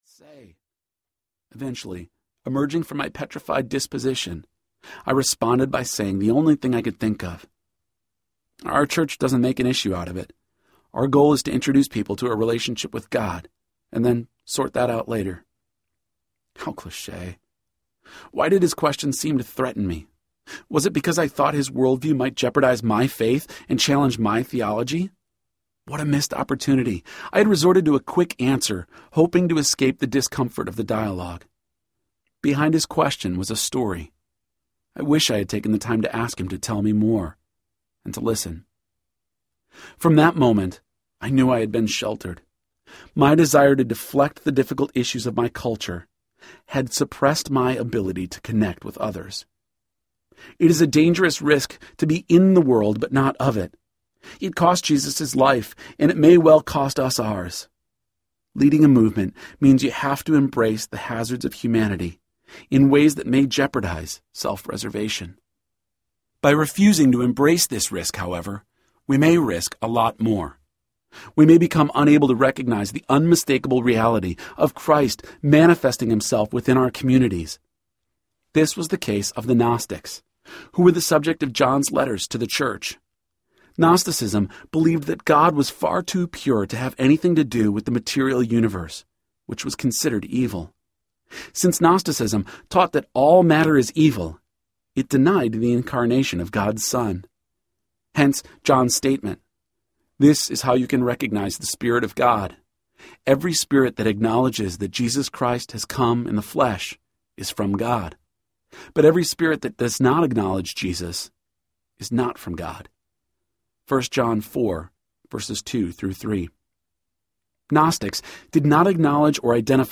Faith in Real Life Audiobook
6.5 Hrs. – Unabridged